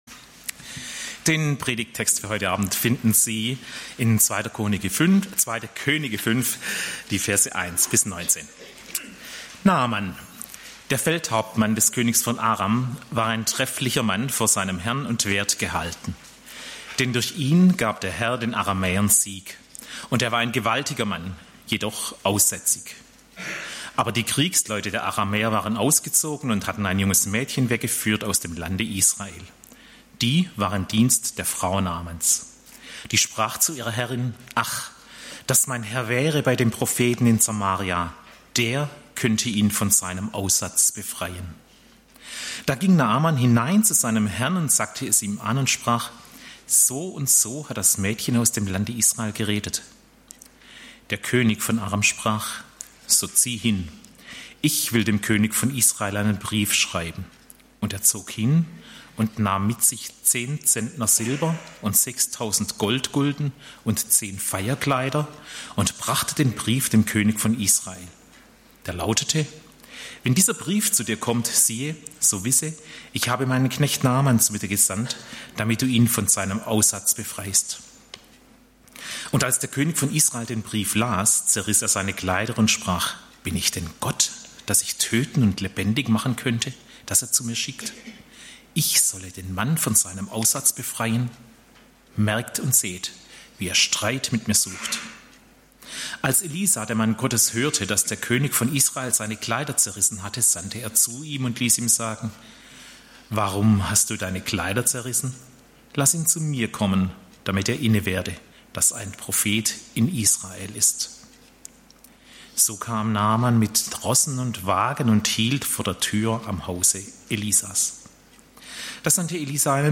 Die Erkenntnis Christi (Phil. 3, 1-11) - Gottesdienst